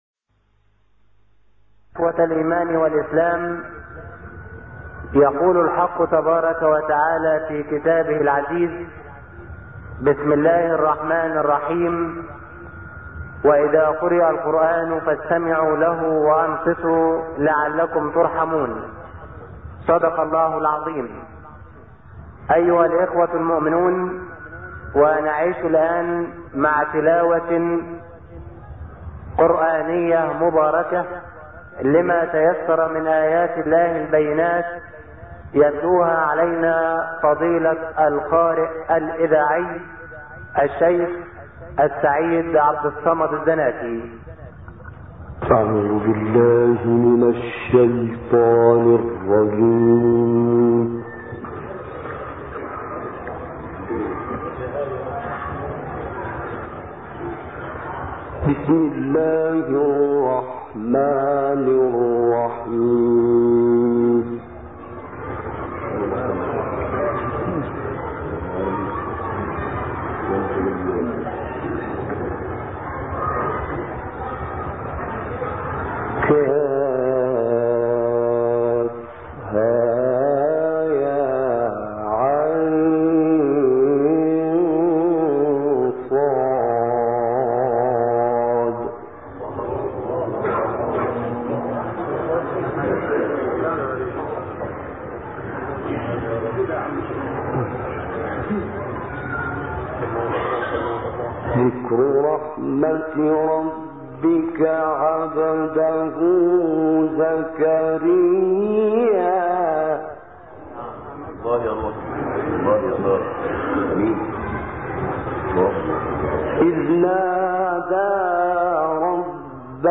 گروه فعالیت‌های قرآنی: تلاوت شنیدنی آیاتی از سوره مریم با صدای سعید عبدالصمد الزناتی را می‌شنوید.
به گزارش خبرگزاری بین المللی قرآن(ایکنا) ، تلاوتی شنیدنی از سعید عبدالصمد الزناتی، قاری مصری که در سال 1986 میلادی در قصر عابدین مصر اجرا شده است، در کانال تلگرام قاریان قرآن ایران منتشر شده است. عبدالصمد الزناتی در این فایل صوتی به تلاوت آیه 1 تا 31 سوره مبارکه مریم می‌پردازد.